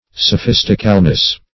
So*phis"tic*al*ness, n.